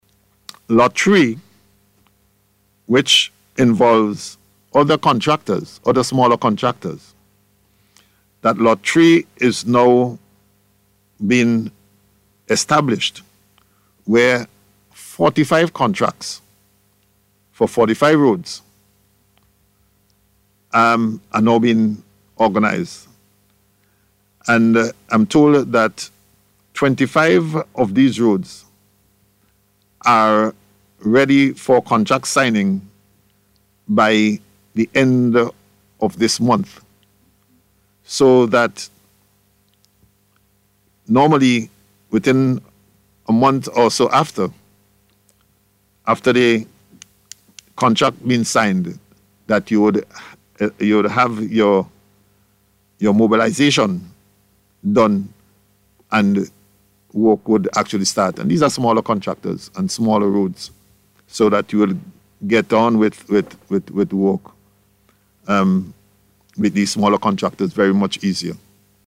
This assurance came from Acting Prime Minister and Minister of Transport and Works Montgomery Daniel, during NBC’s Face to Face programme this week.